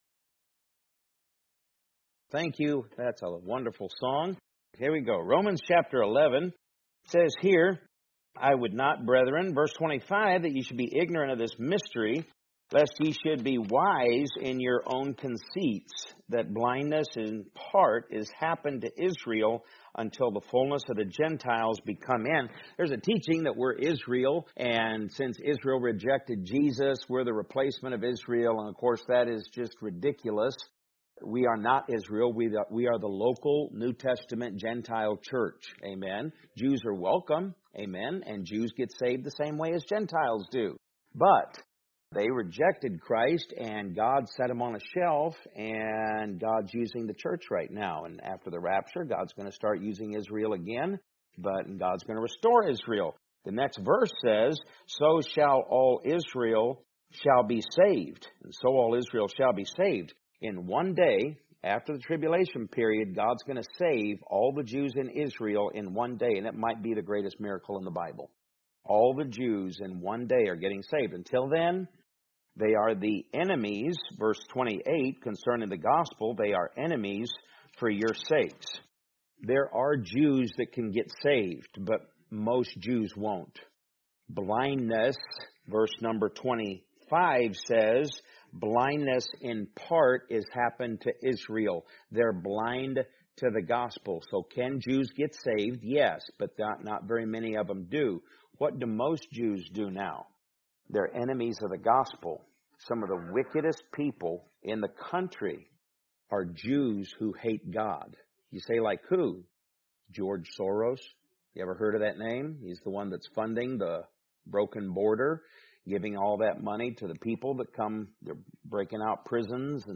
Podcast (sermon-podcast): Play in new window | Download